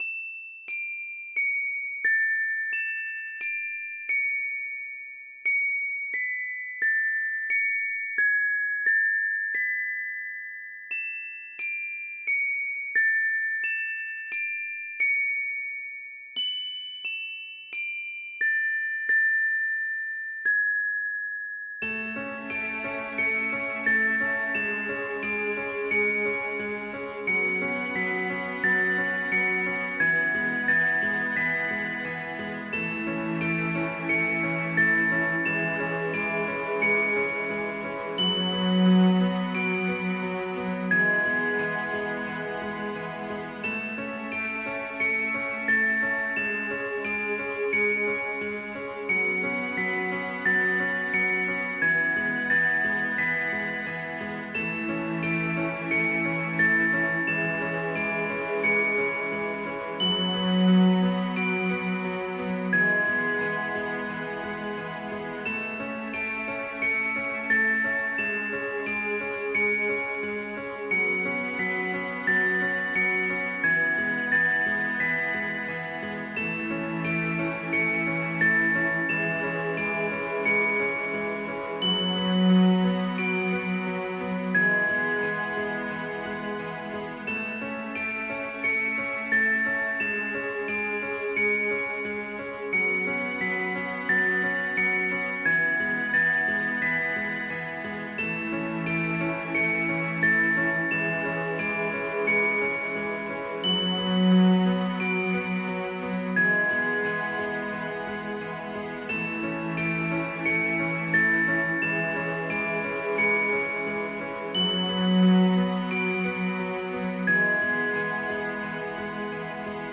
Contemplative, Pretty, Theater Music